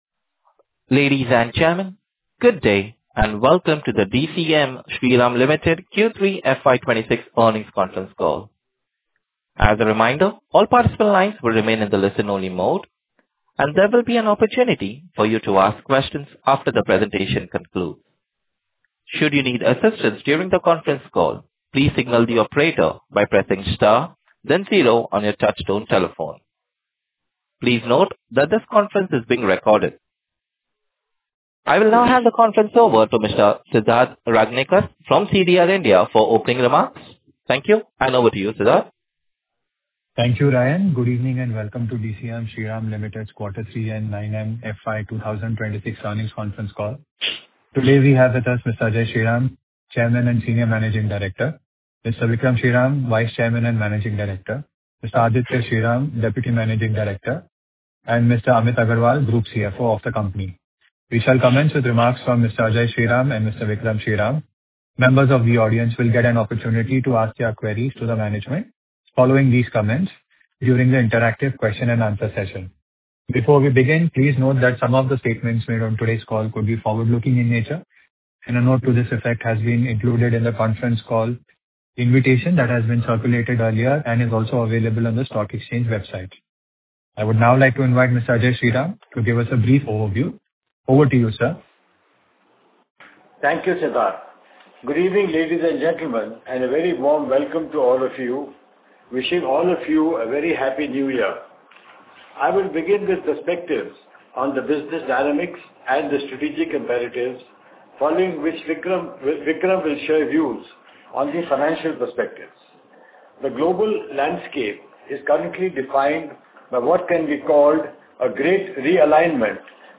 Concalls
DCM Shriram Limited - Q3 FY26 Earnings Call - Audio Recording.mp3